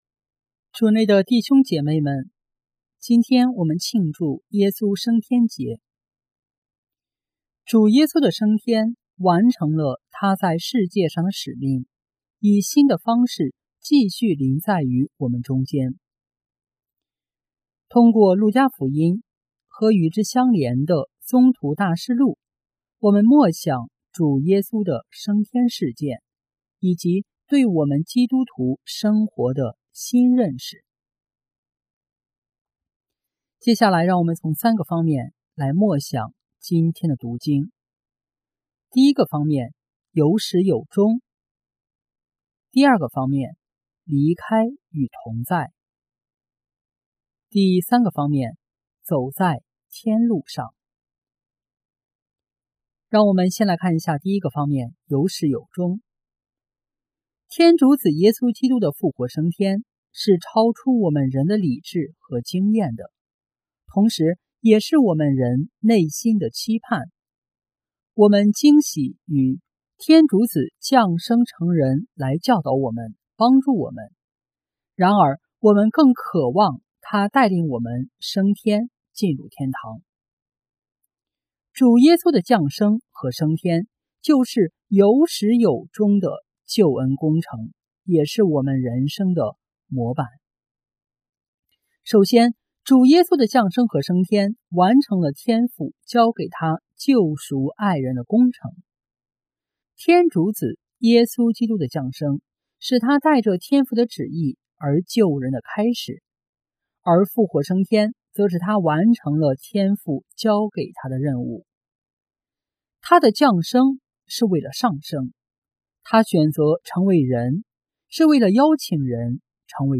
【主日证道】| 走在天路上（耶稣升天节）